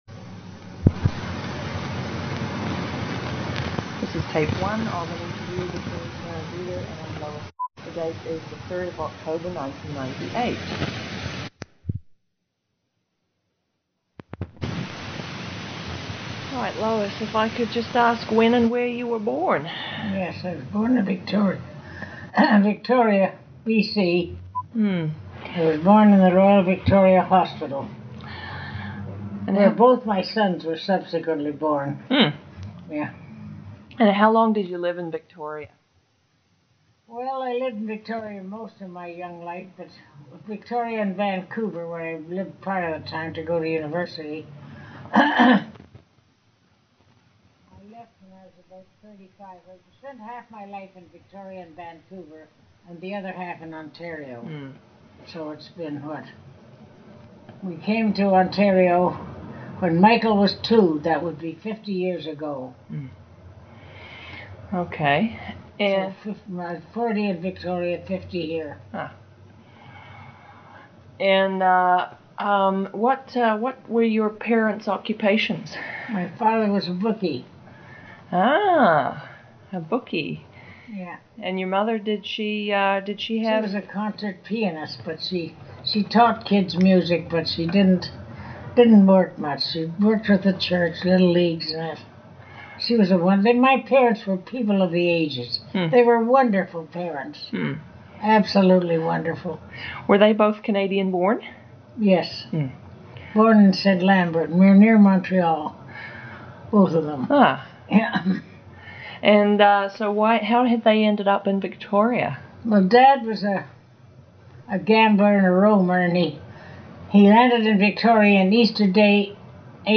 Lesbian and Bisexual Women in English Canada audio history collection